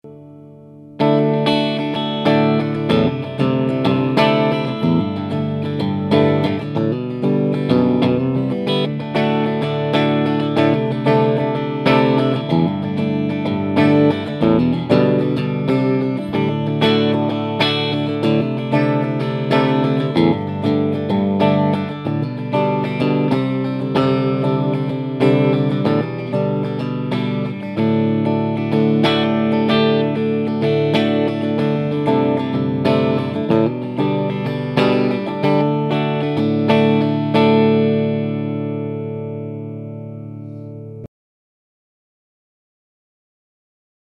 Voici une serie d'enregistrements qui ont été fait sur le Ten, l'ampli 10 watt de Pasqualiamps.
Le baffle utilisé est un "closed back" ave 2 HP Celestion G12-H. Les 3 premières plages ne contiennent aucun effet.
Plage 3 "Pop": Rythmique "
Réglages Tactile Custom: Micro manche simple (splité) Tone 100 % - Volume 100 %
Réglage Pasqualiamps Ten: Tone 7.5 - Volume 1
Pas d'effets.